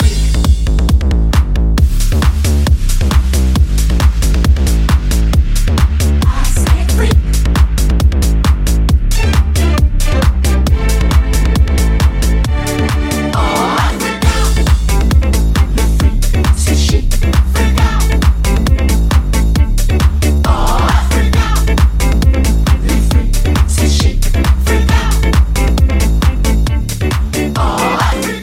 Genere: club, remix